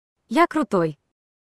Звуки Siri